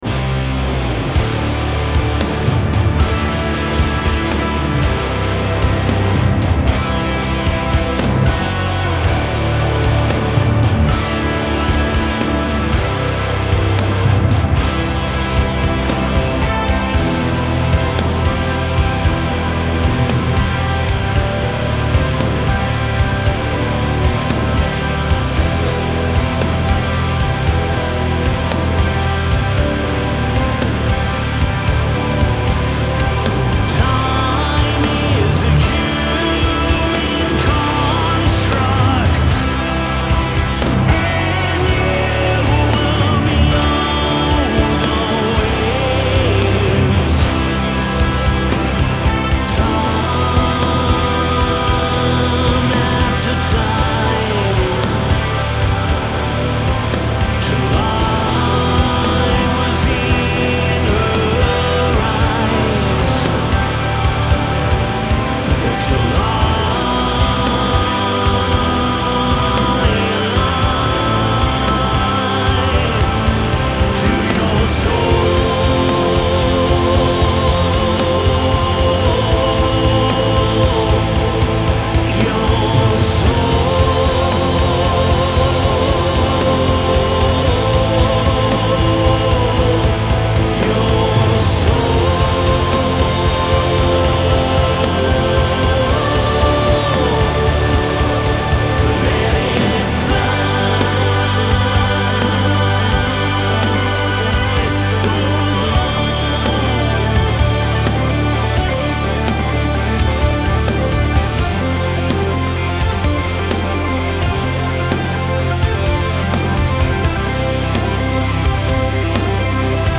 Genre : Progressive Rock, Progressive Metal